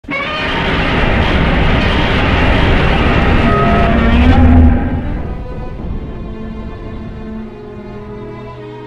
File:Shin Godzilla 4th Form roar.ogg